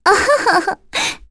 Aisha-Vox_Happy1.wav